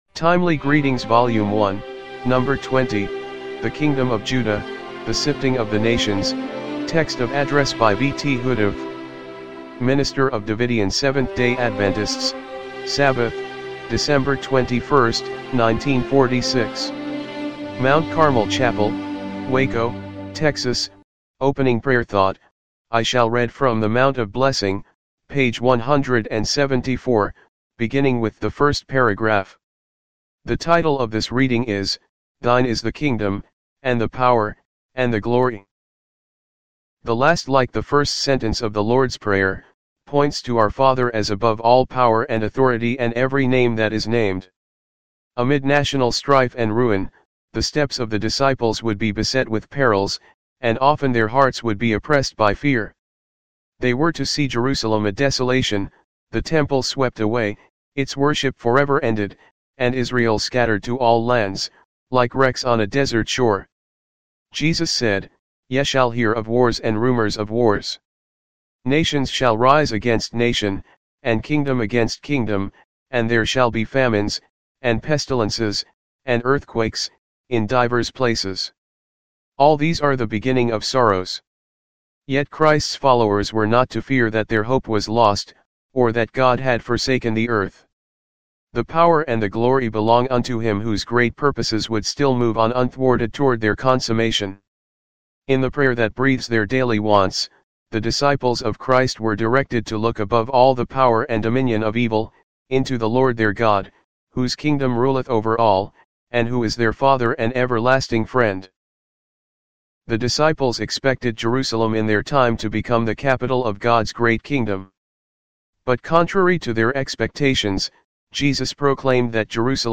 MT. CARMEL CHAPEL